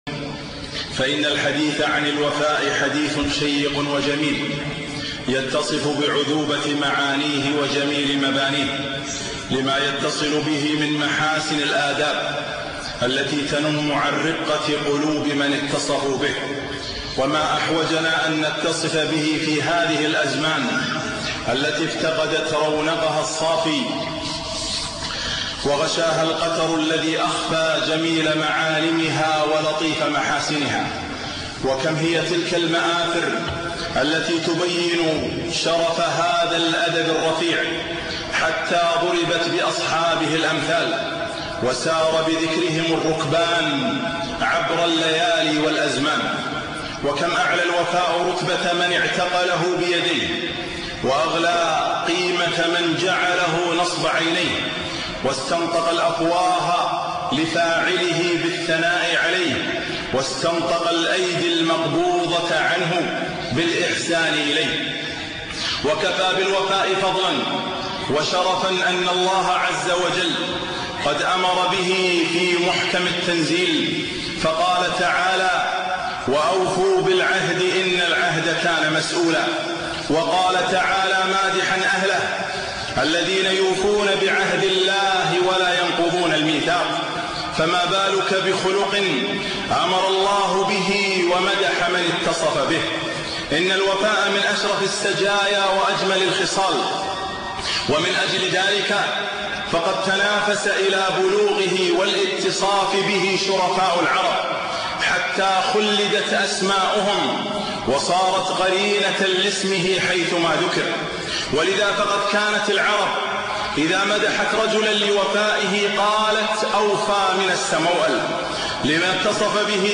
خطبة - الوفاء